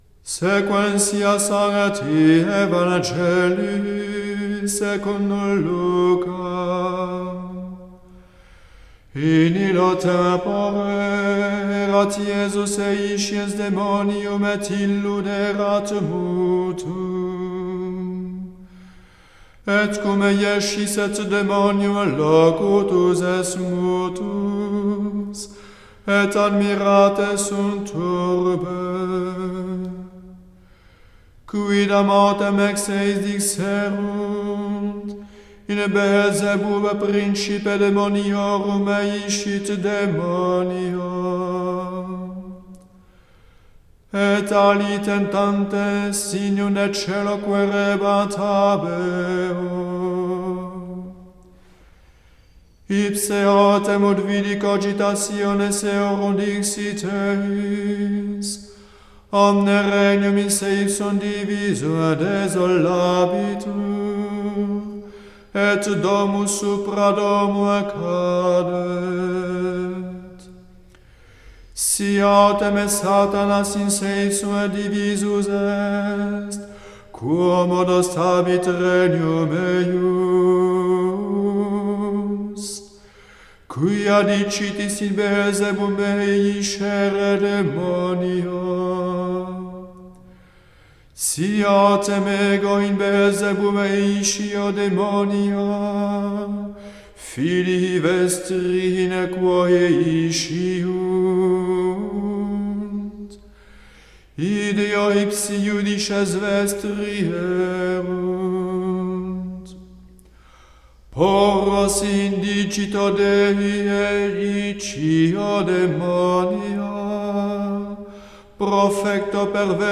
Evangile du 3e dimanche de car�me NB.